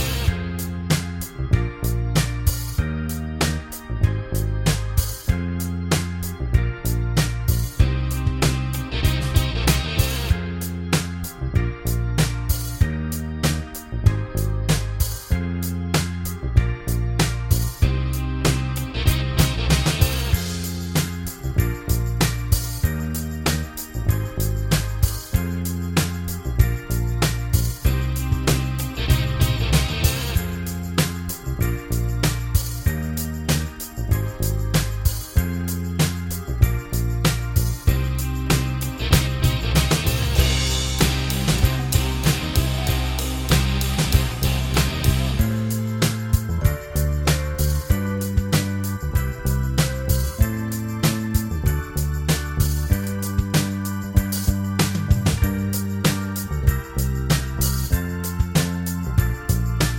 Minus Main Guitars For Guitarists 3:05 Buy £1.50